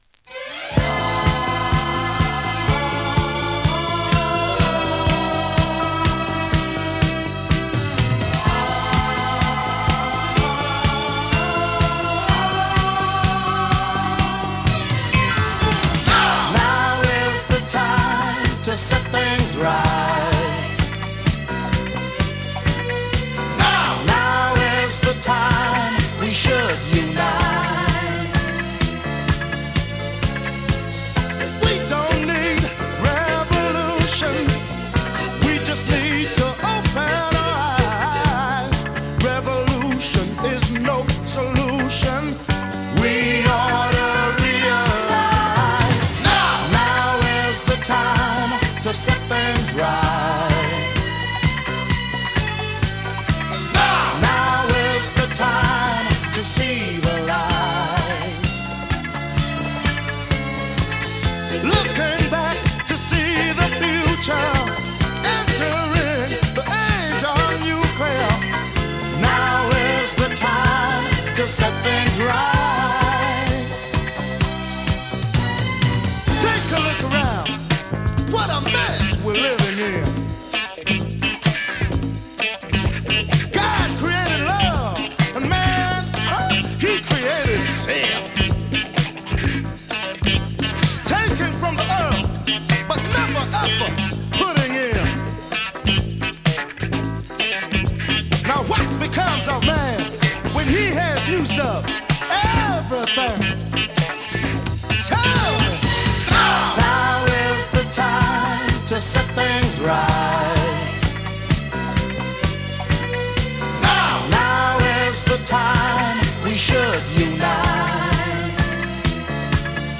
SOUL FUNK 45
ポジティヴな高揚感にあふれたフィリー系ダンサー！
[2track 7inch]＊音の薄い部分でチリチリ・ノイズ。